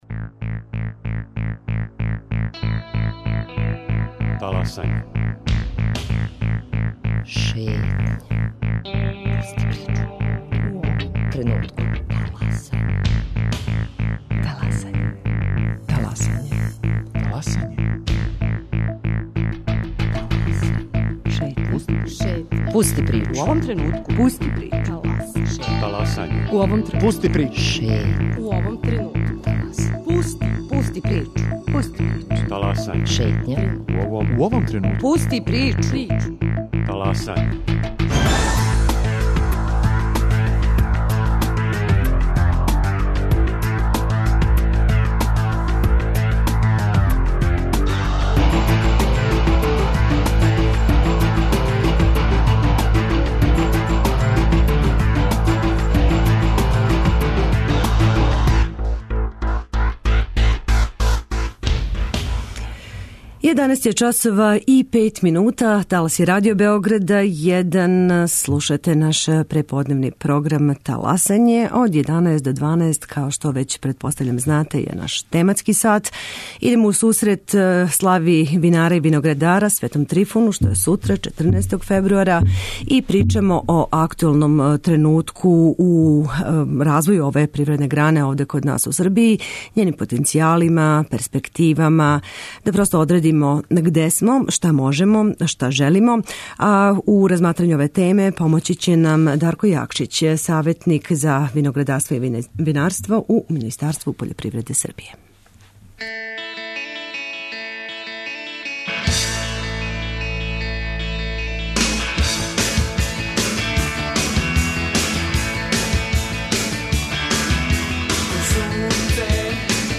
Гост у студију
Путем телефона